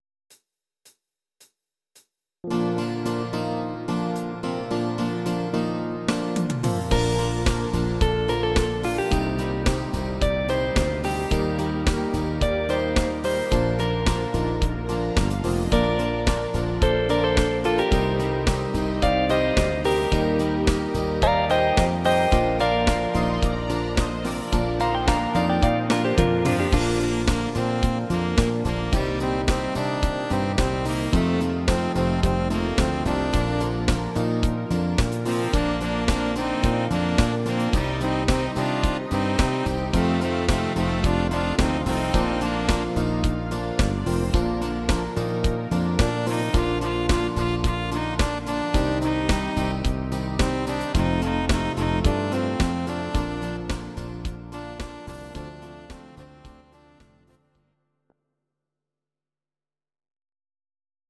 Audio Recordings based on Midi-files
Ital/French/Span